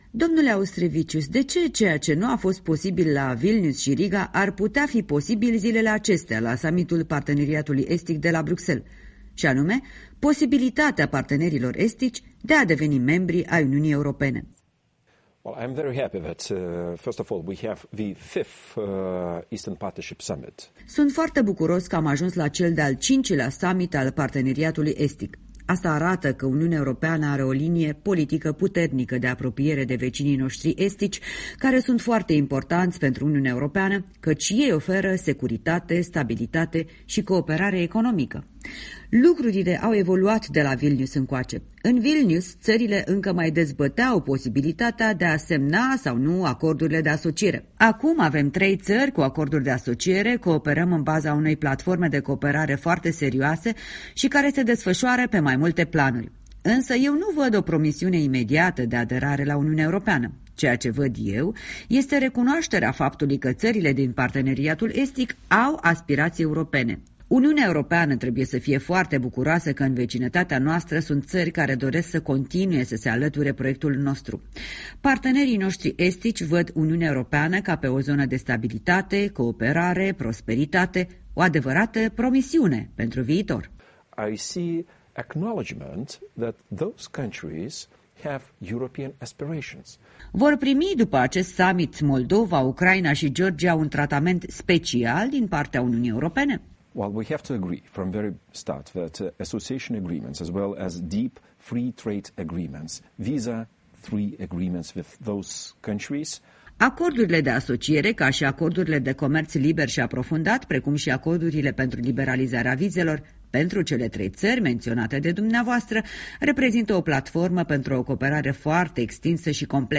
Interviu cu europarlamentarul raportor pentru drepturile omului în vecinătatea Uniunii Europene.